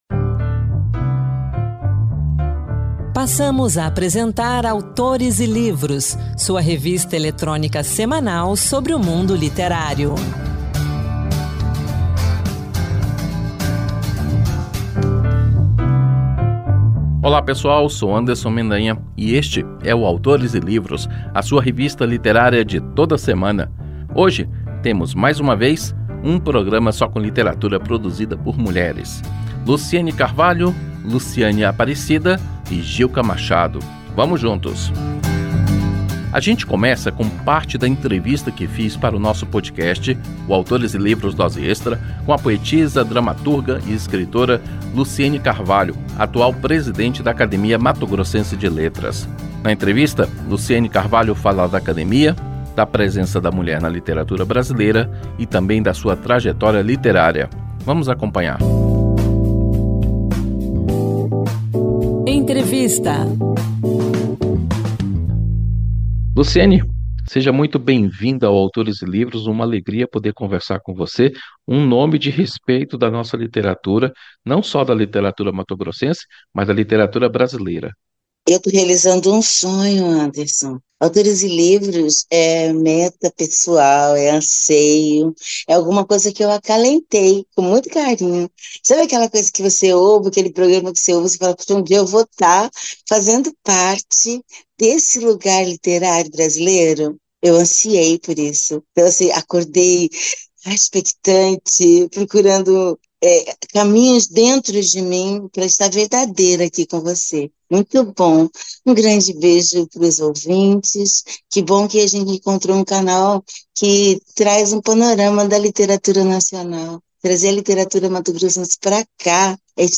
Na entrevista